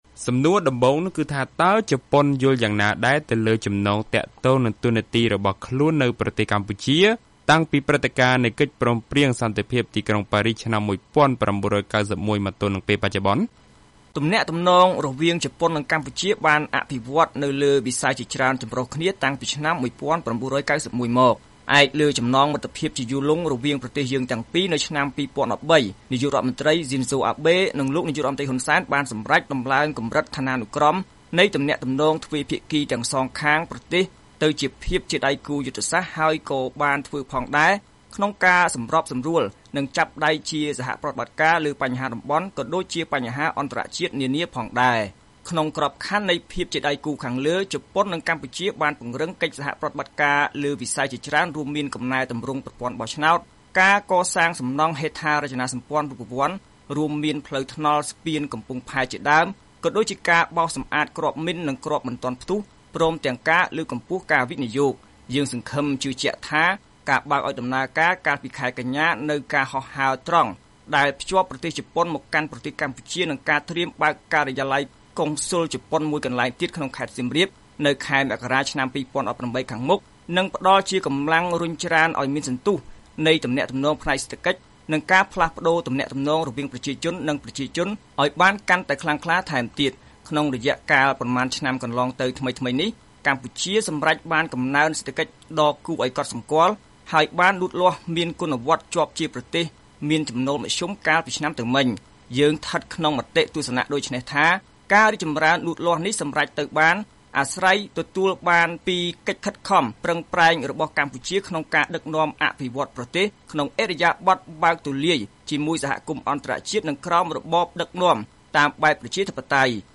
បទសម្ភាសន៍ VOA៖ ឯកអគ្គរាជទូតជប៉ុនមានសុទិដ្ឋិនិយមអំពីនយោបាយ និងទំនាក់ទំនងជាមួយកម្ពុជា